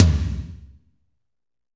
Index of /90_sSampleCDs/Northstar - Drumscapes Roland/DRM_Fast Rock/TOM_F_R Toms x
TOM F RLO00L.wav